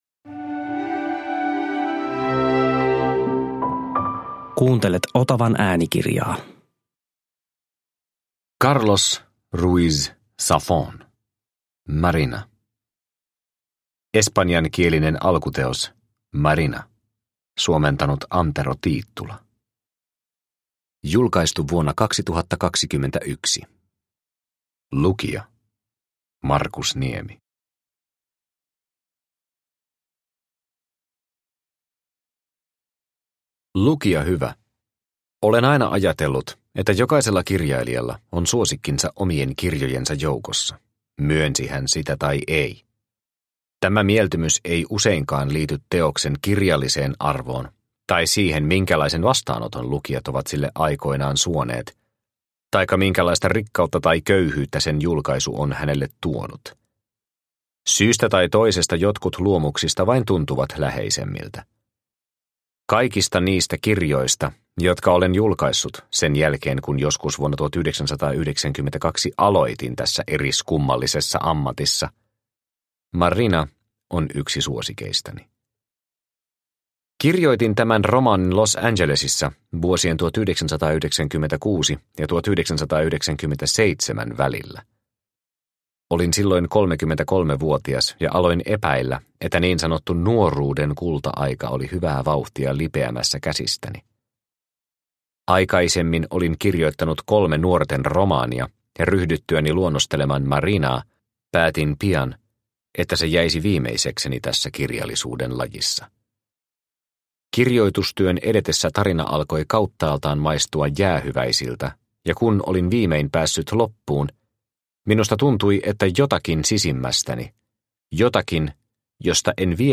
Marina – Ljudbok – Laddas ner